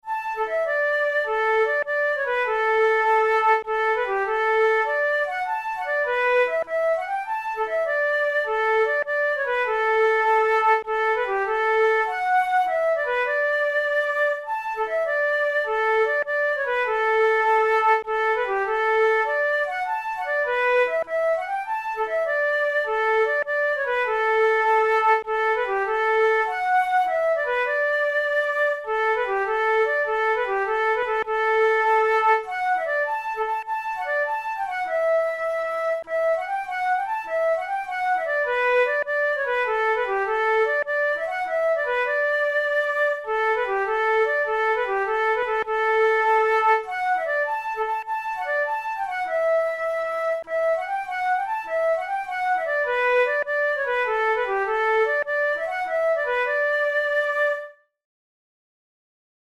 Pipe march
Categories: Celtic Music Contemporary Marches Difficulty: intermediate